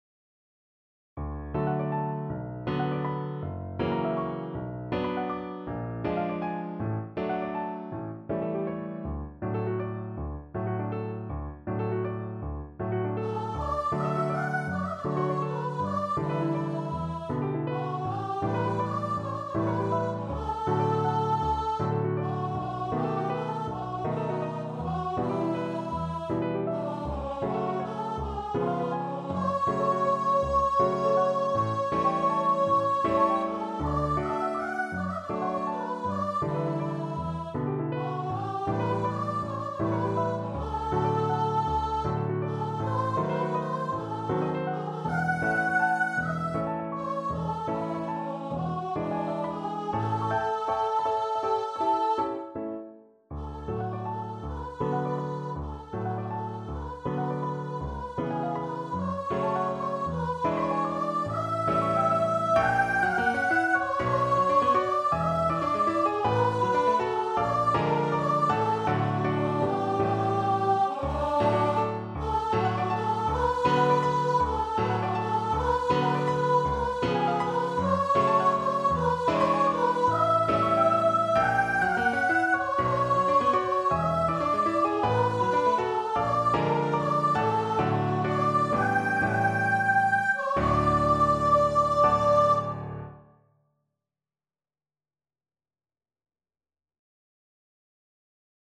Voice
D major (Sounding Pitch) (View more D major Music for Voice )
6/8 (View more 6/8 Music)
Classical (View more Classical Voice Music)